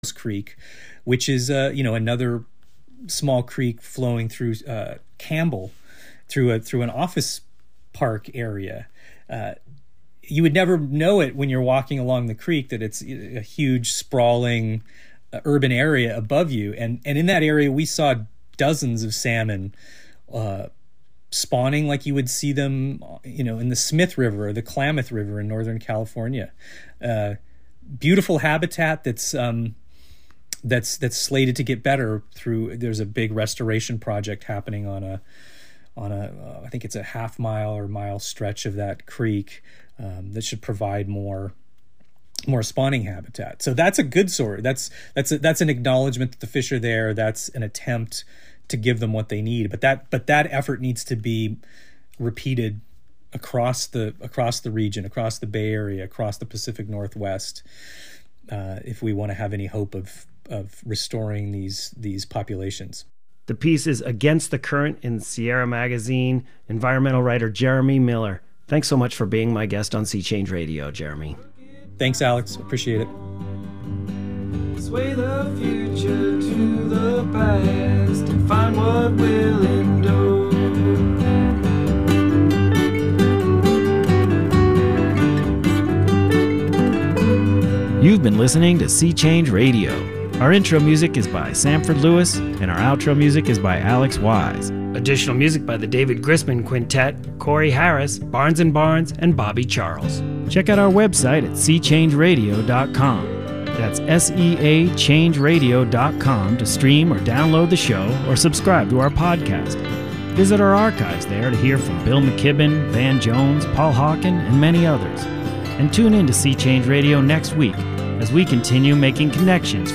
Every first Friday from 9:30 a.m. to 10:00 a.m. A monthly show interviewing people within the disability community.